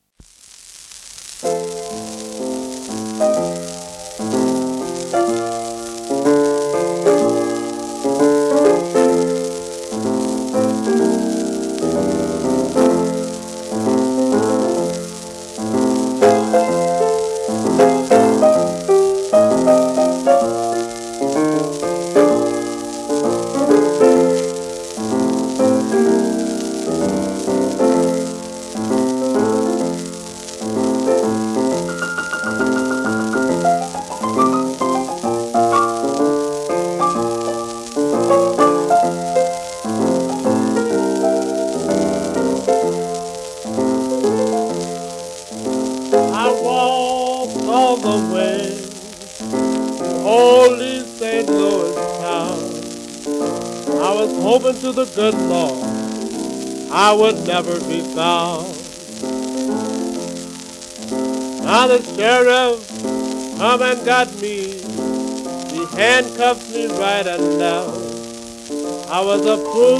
盤質B+ *薄い面スレ、一部溝荒れ有
1940年録音